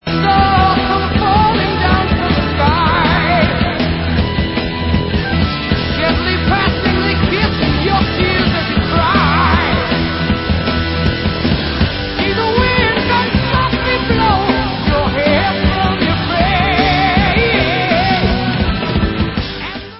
sledovat novinky v oddělení Hard Rock